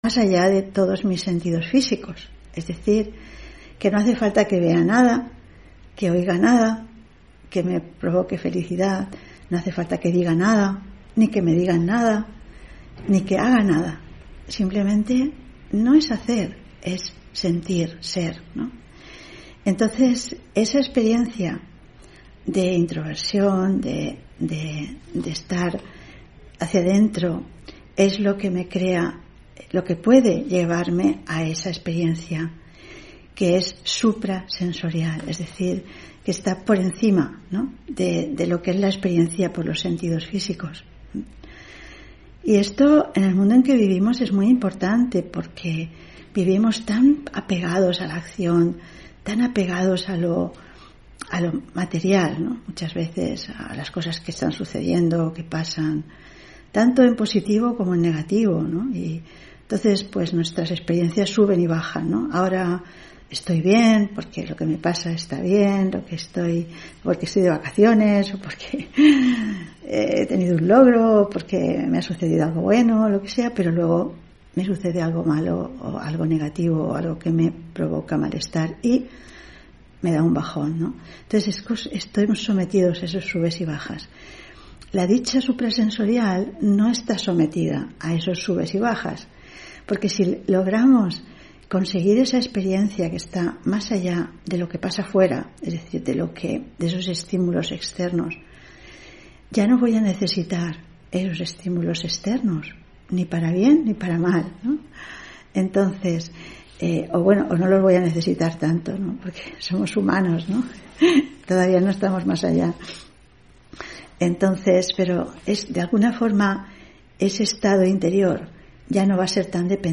Meditación de la mañana: Dicha suprasensorial